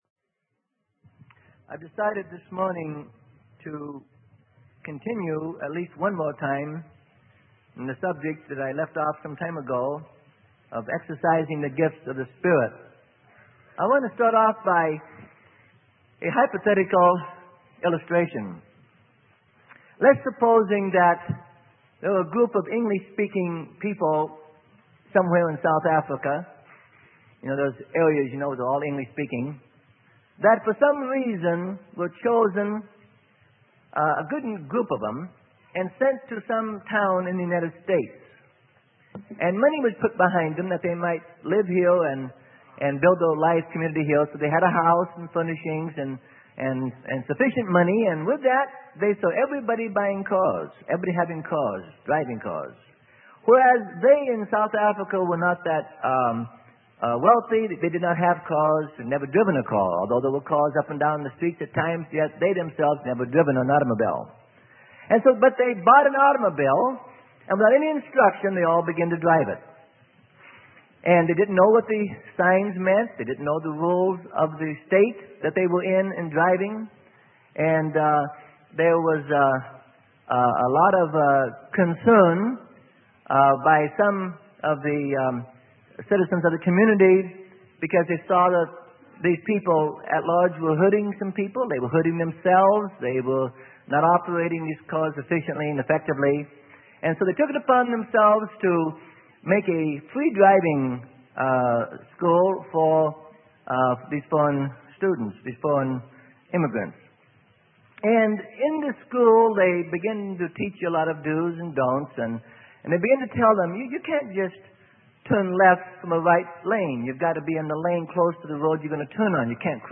Sermon: Principles in Exercising the Gifts of the Spirit - Part 10 - Freely Given Online Library